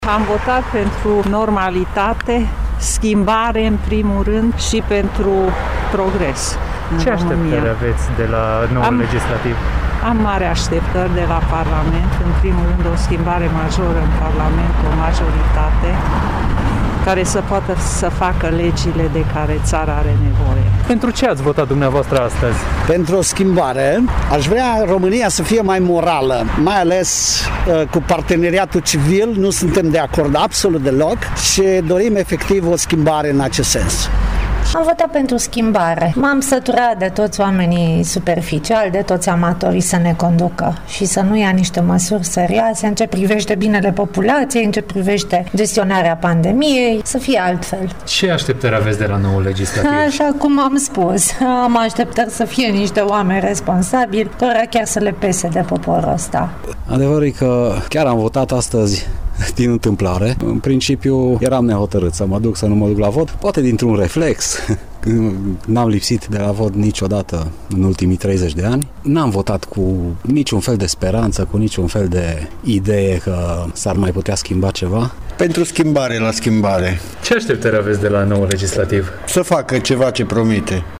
Unii alegători întâlniți azi la urne
voxuri-alegeri-final.mp3